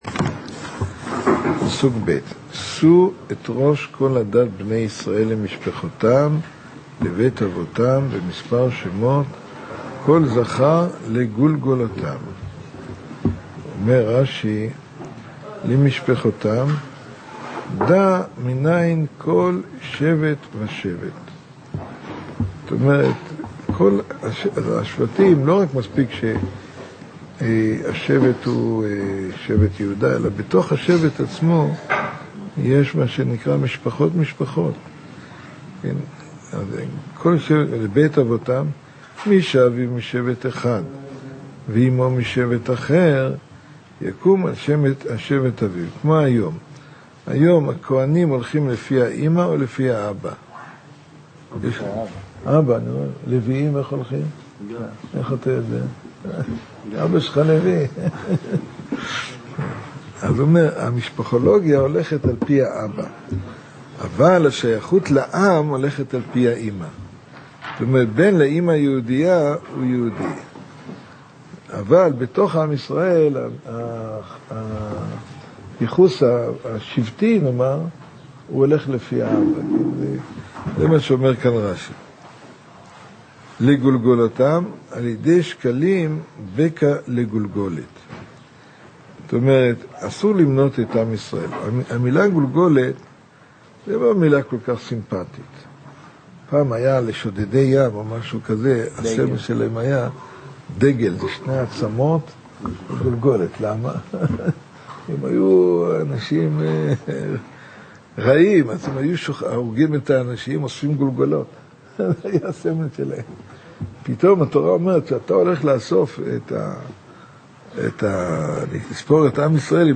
לימוד בוקר